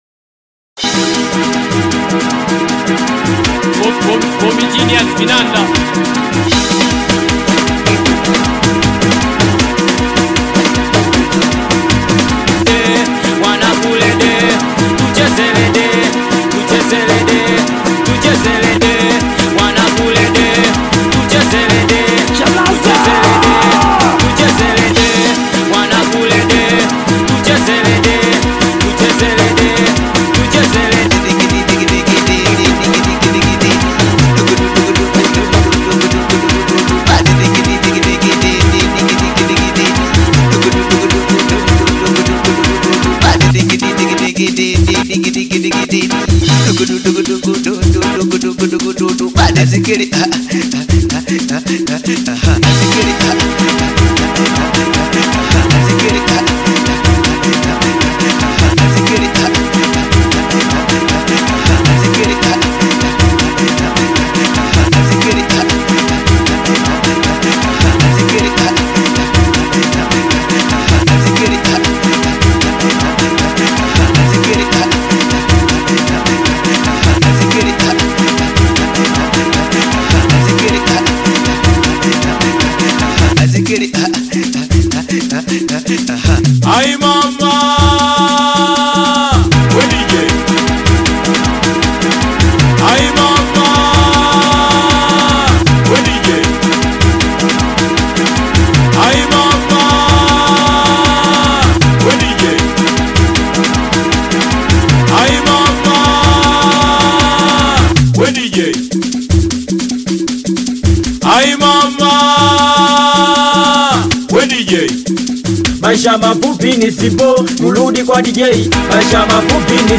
Tanzanian Top Upcoming singeli DJ and artist
singeli beat song
Singeli